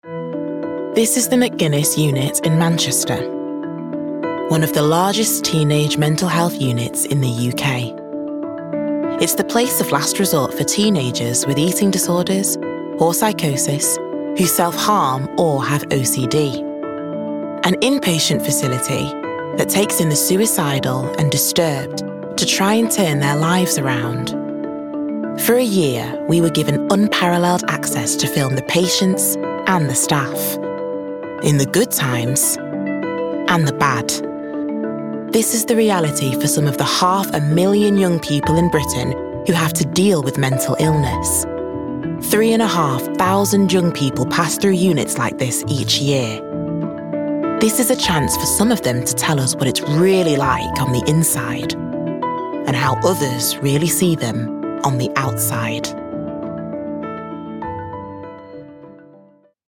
Documentary Showreel
Female
Manchester